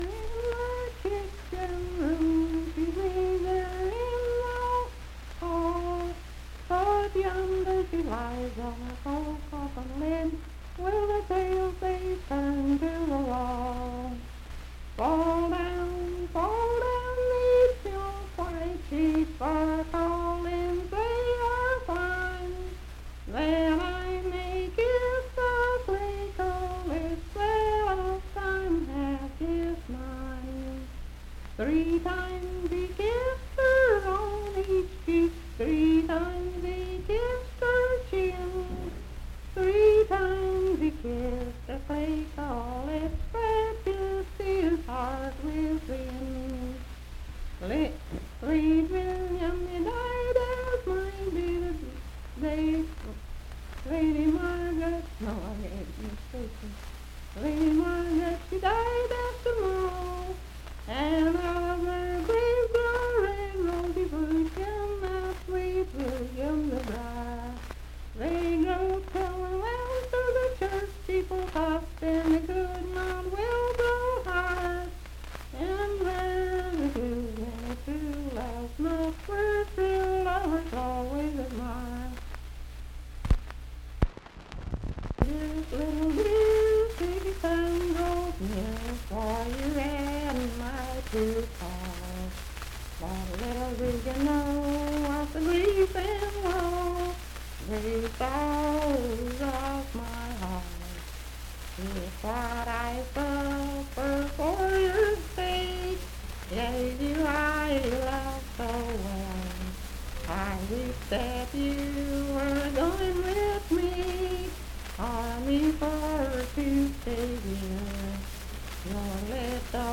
Unaccompanied vocal music
Voice (sung)
Hardy County (W. Va.), Moorefield (W. Va.)